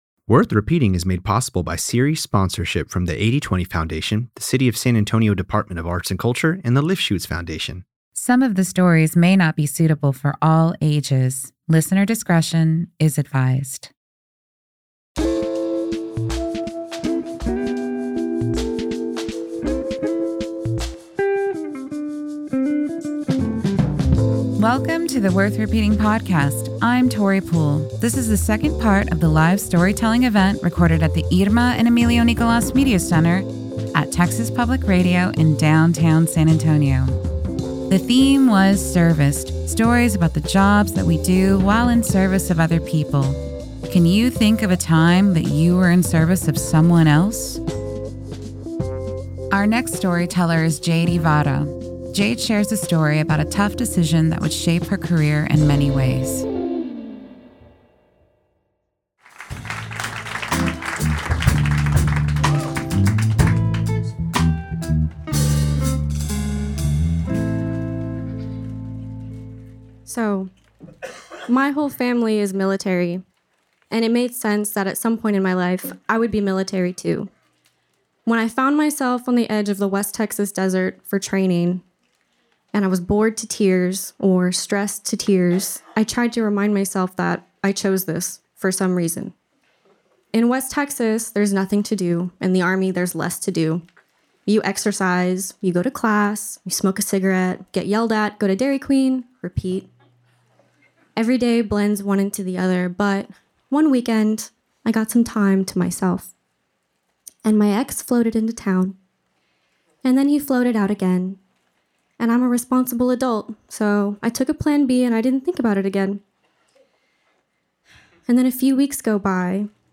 In this episode of the Worth Repeating podcast, storytellers share stories on the theme, Serviced.